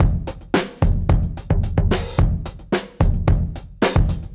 Index of /musicradar/dusty-funk-samples/Beats/110bpm
DF_BeatA_110-04.wav